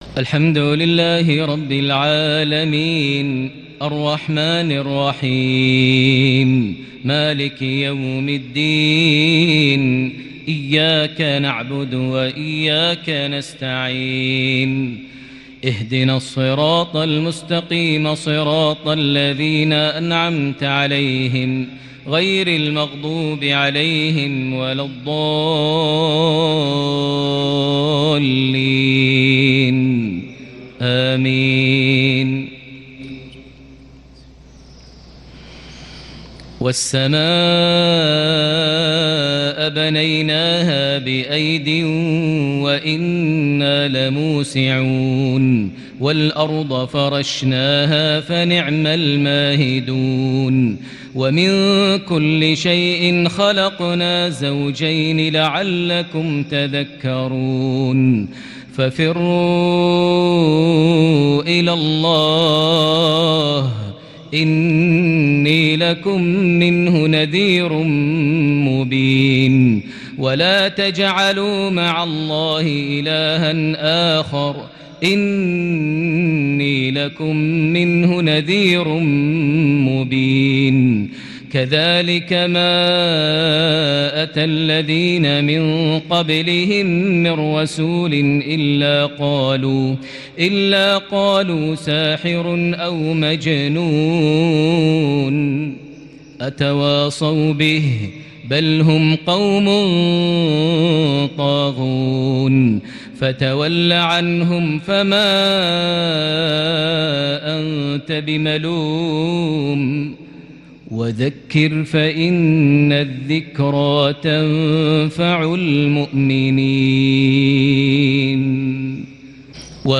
(ففروا إلى الله) مغربية فريدة لخواتيم سورة الذاريات 6 شعبان 1442هـ > 1442 هـ > الفروض - تلاوات ماهر المعيقلي